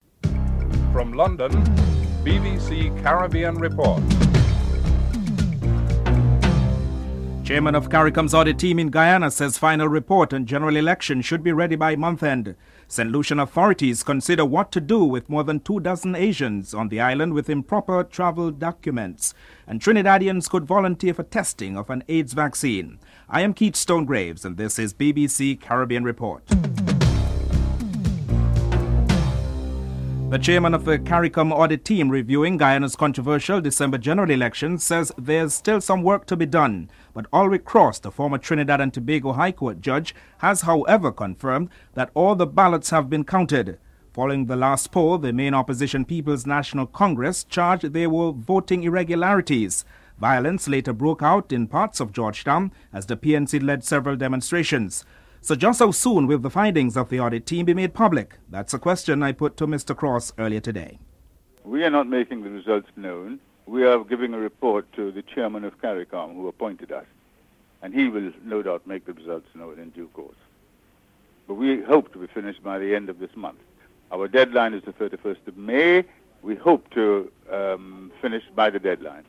11. Recap of top stories (14:37-15:10)